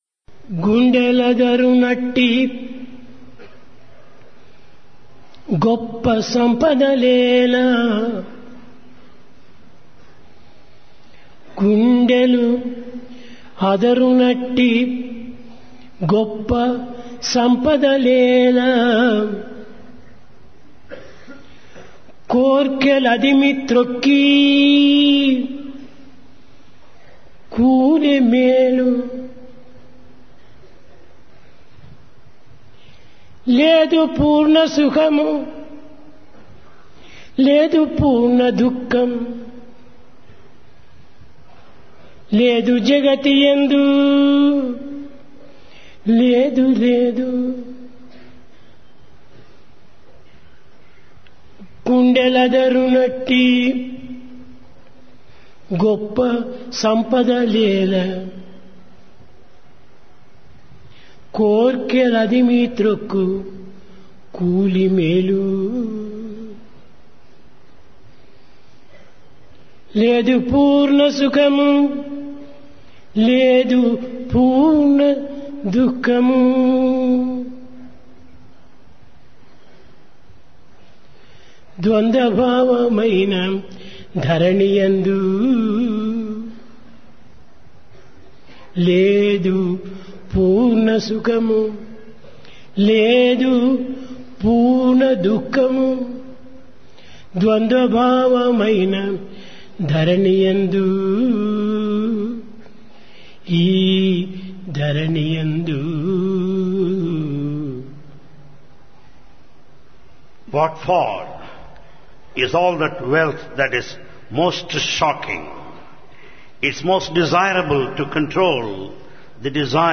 Divine Discourse of Bhagawan Sri Sathya Sai Baba
Place Prasanthi Nilayam Occasion Dasara